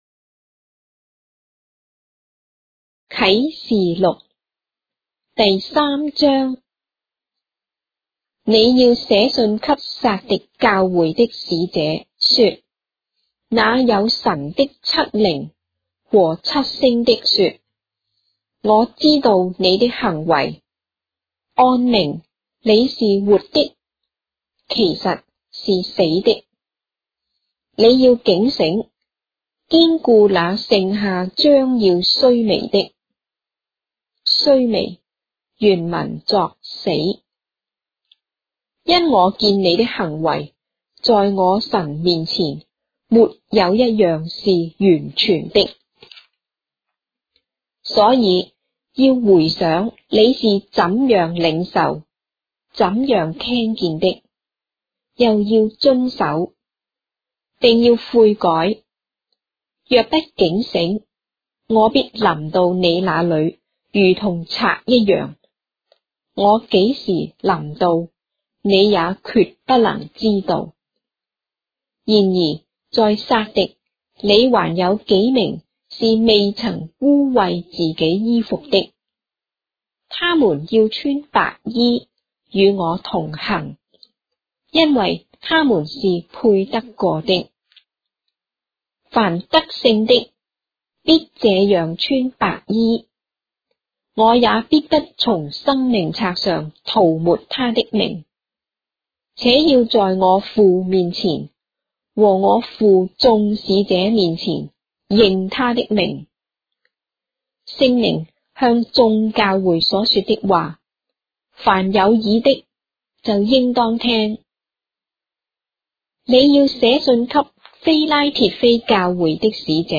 章的聖經在中國的語言，音頻旁白- Revelation, chapter 3 of the Holy Bible in Traditional Chinese